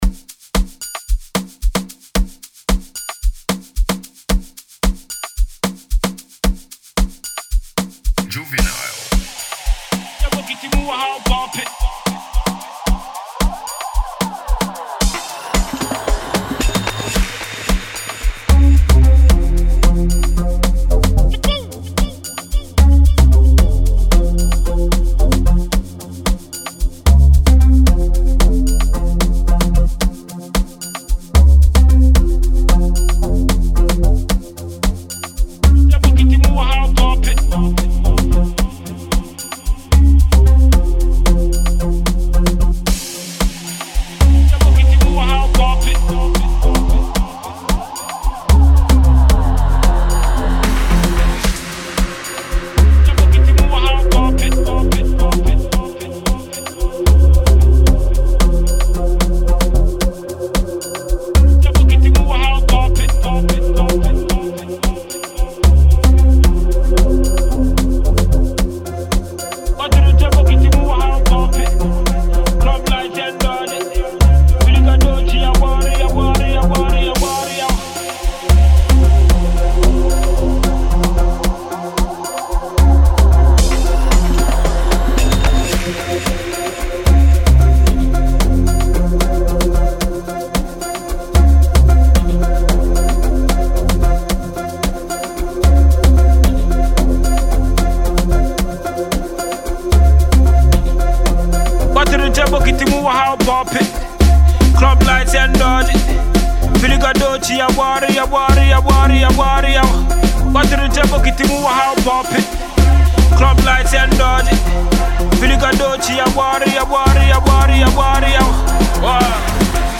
freshly released from the studios
Enjoy this dope banger.